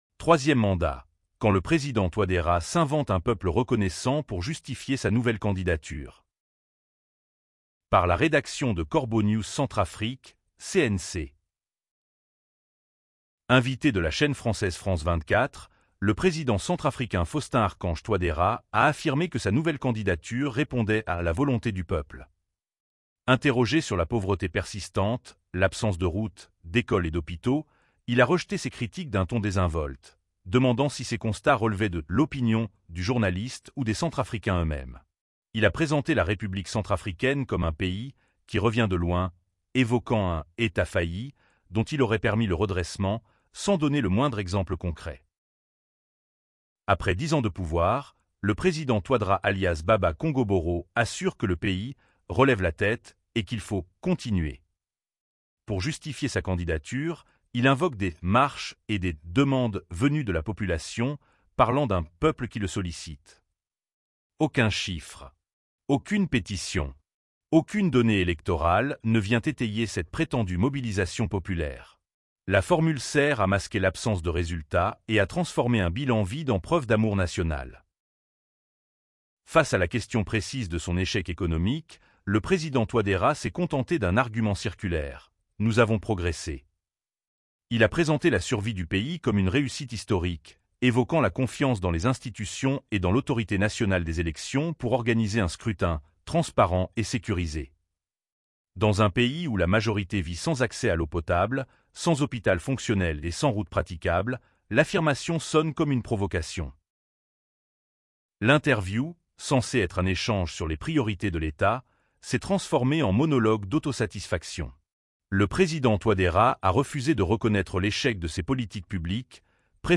Invité de la chaine française France 24, le président centrafricain Faustin-Archange Touadéra a affirmé que sa nouvelle candidature répondait à “la volonté du peuple”.
L’interview, censée être un échange sur les priorités de l’État, s’est transformée en monologue d’autosatisfaction.